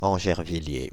Angervilliers (French pronunciation: [ɑ̃ʒɛʁvilje]